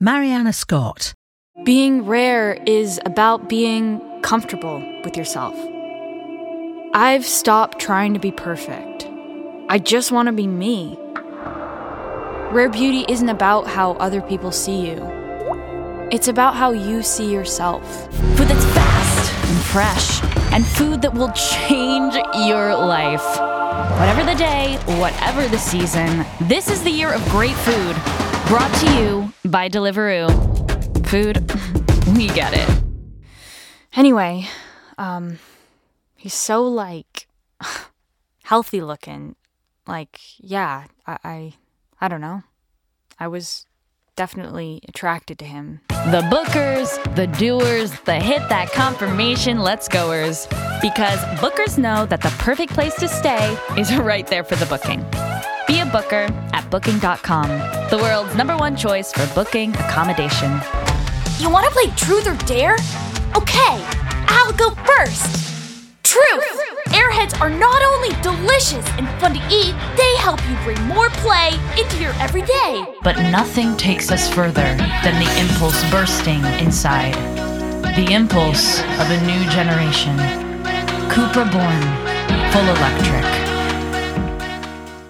Description: American: vibrant, fresh, engaging
Age range: 20s - 30s
Commercial 0:00 / 0:00
American*, Californian, West Coast